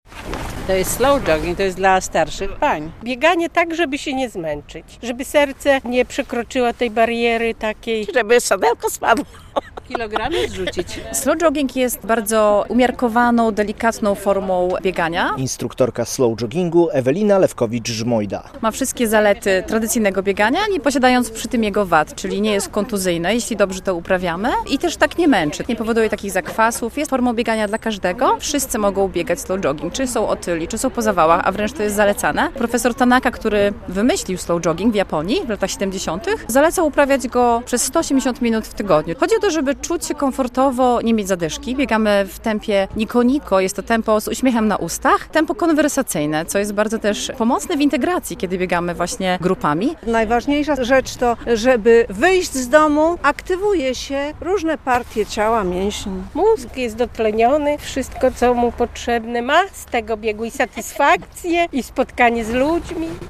Slow jogging w Białymstoku - relacja